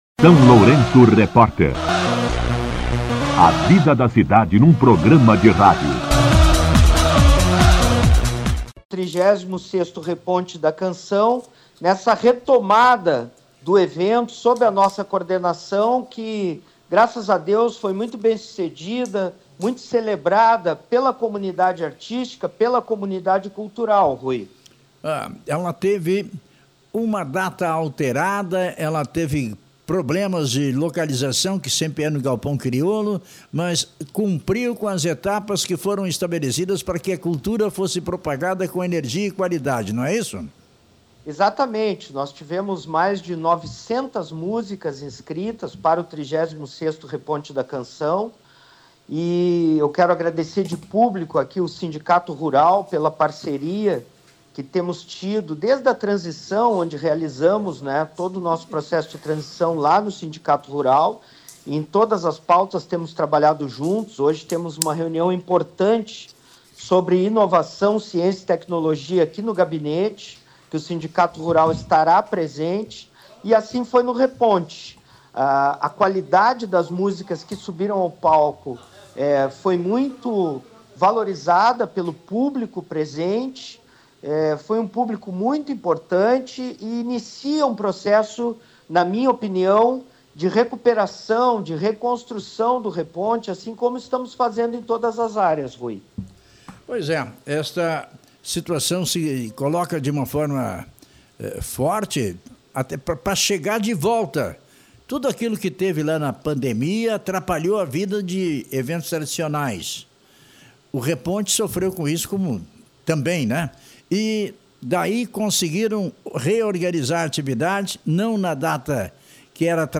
Entrevista com O prefeito Zelmute Marten